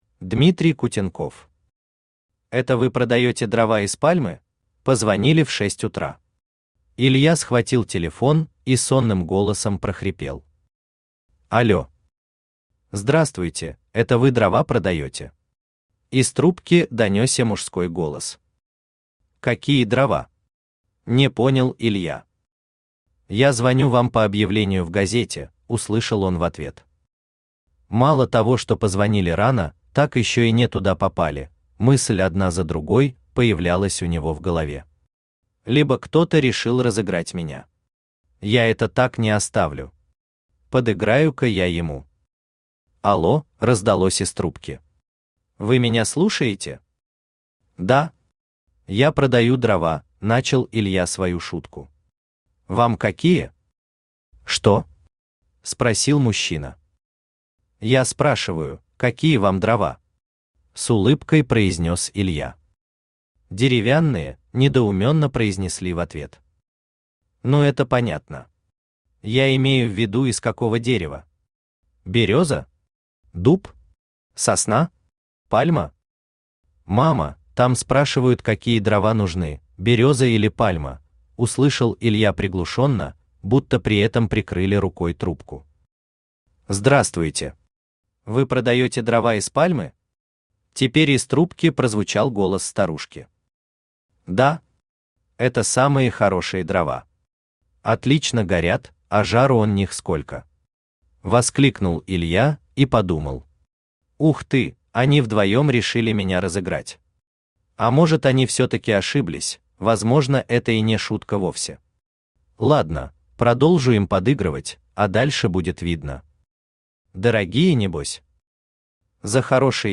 Аудиокнига Это вы продаёте дрова из пальмы?
Автор Дмитрий Кутенков Читает аудиокнигу Авточтец ЛитРес.